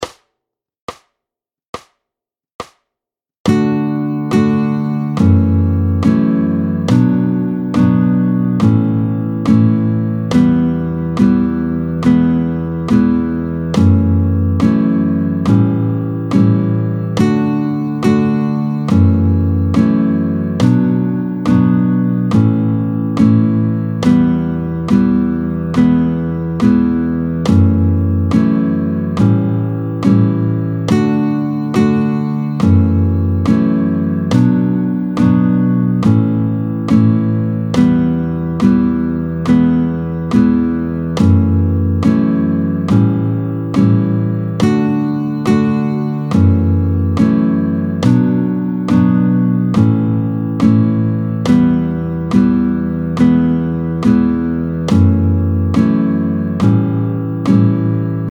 21-03 Modulation en La, tempo 70